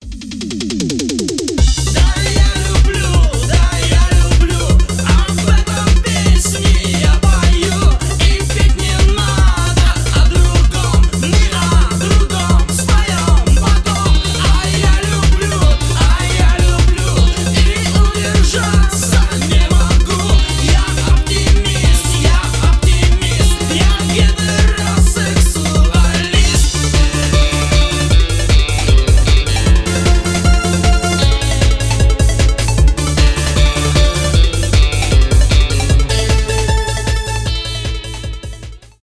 synth rock , synth pop
психоделический рок
pop rock , рок